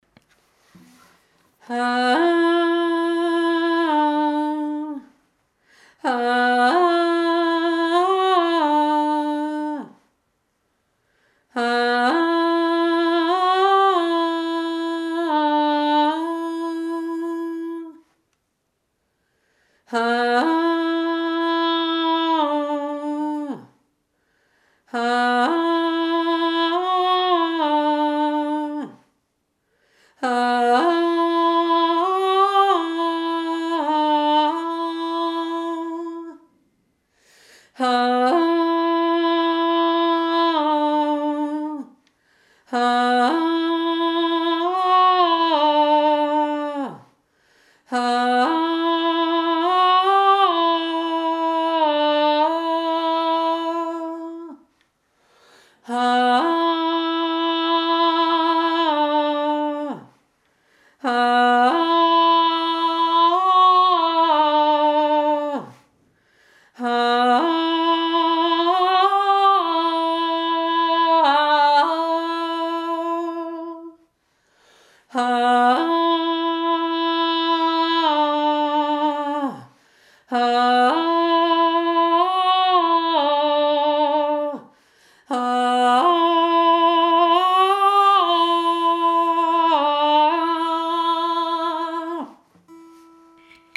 Dieses eine ist ein Lied ohne Worte - ein Klagegesang.
KLAGELIED 1. Stimme